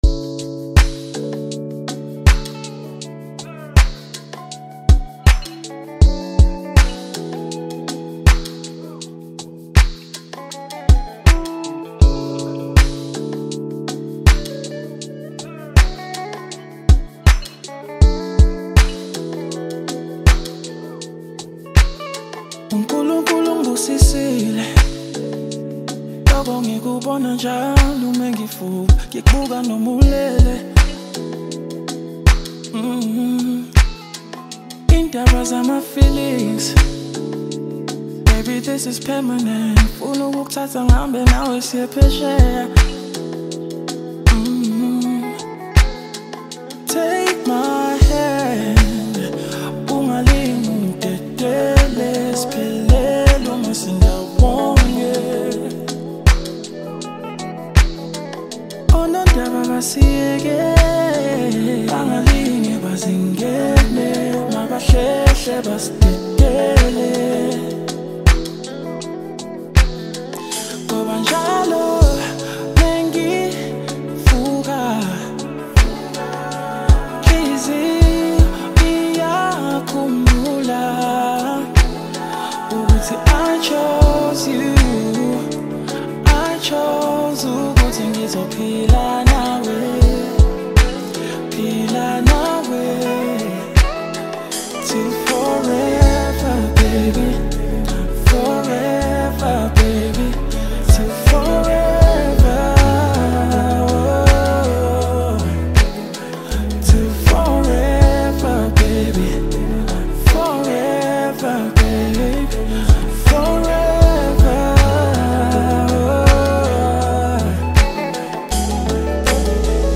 South African singer